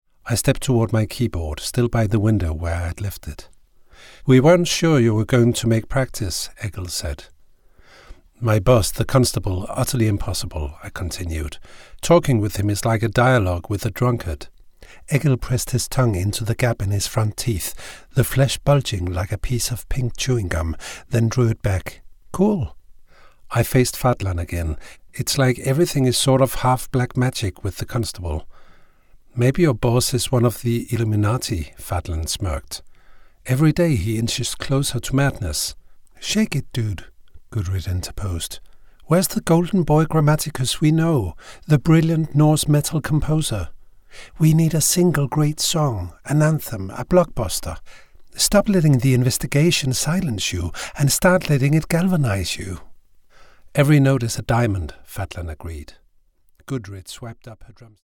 Deep, warm voice.
Audiobooks
English With Danish Accent
0801The_Berserkers_-_audiobook_UK.mp3